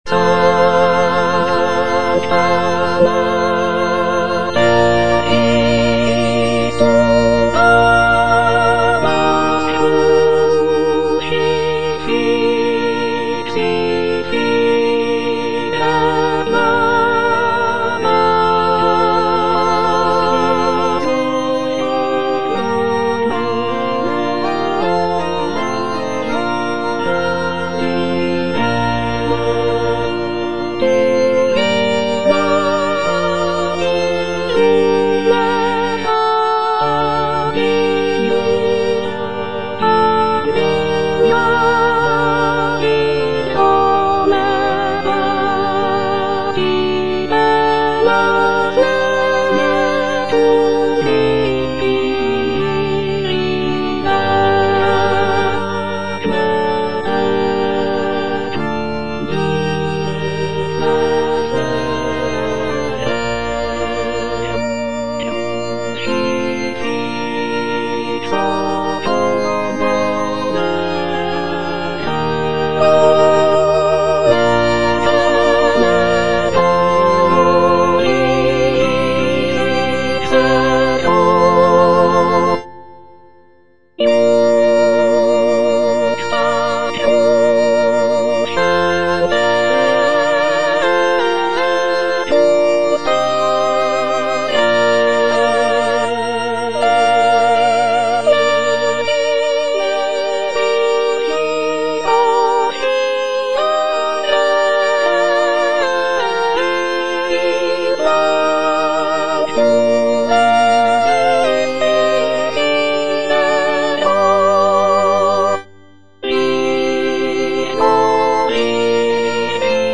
(soprano I) (Emphasised voice and other voices) Ads stop
is a sacred choral work